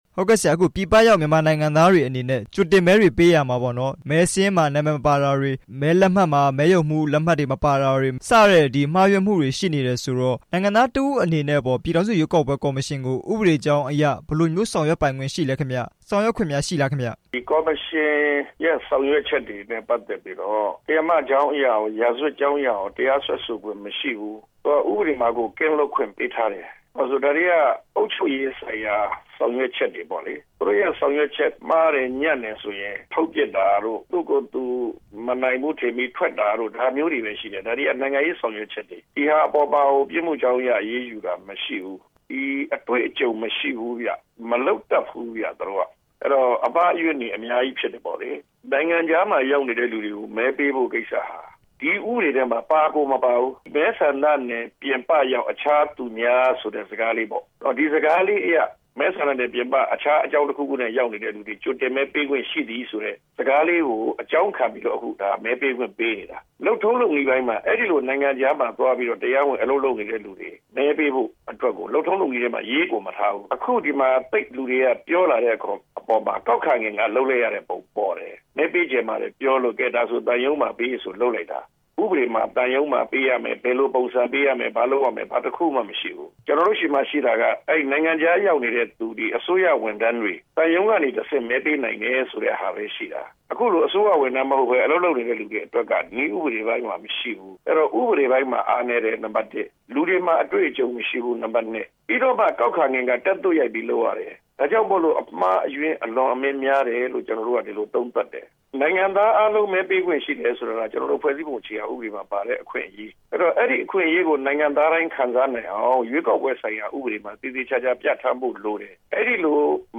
ကြိုတင်မဲ မှားယွင်းမှု ရှေ့နေ ဦးကိုနီနဲ့ မေးမြန်းချက်